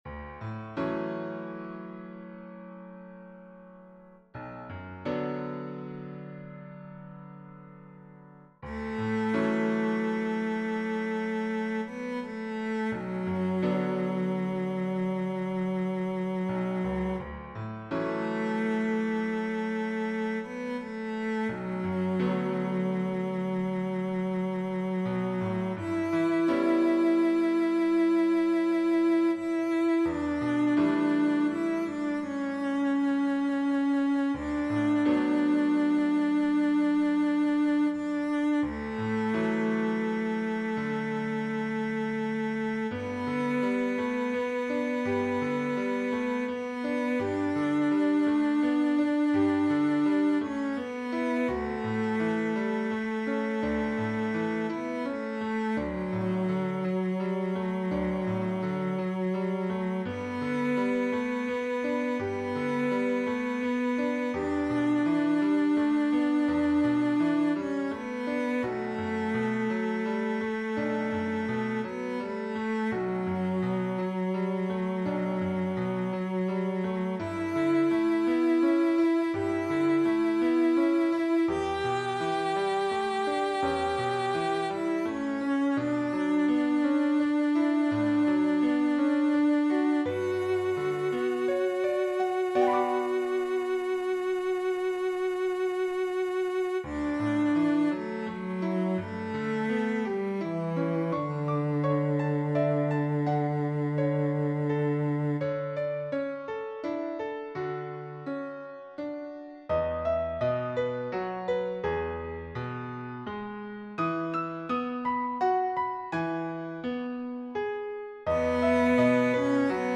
Cello solo with piano accompaniment.
Voicing/Instrumentation: Cello solo We also have other 122 arrangements of " Silent Night ".